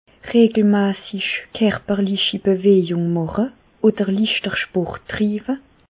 Bas Rhin
Reichshoffen